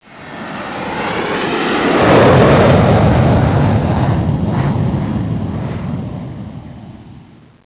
Jet
Jet.wav